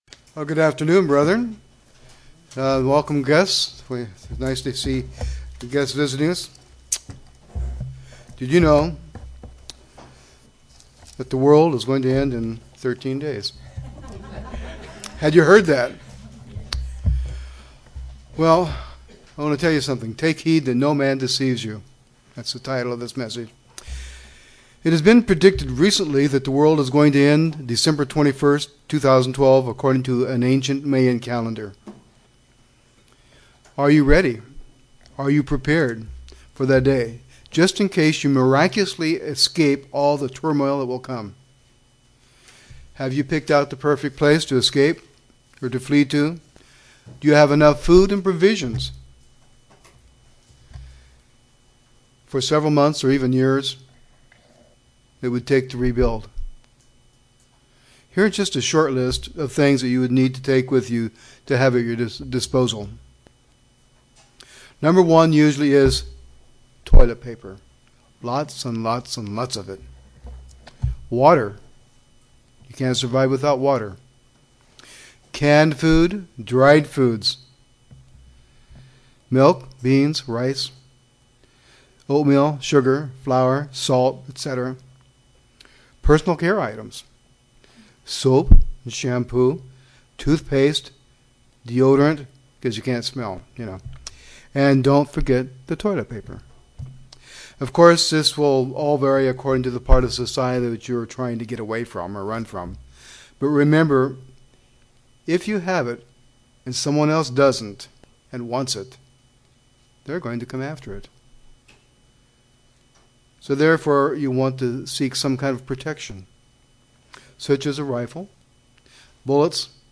Given in Northwest Arkansas
(Matthew:24:4) UCG Sermon Studying the bible?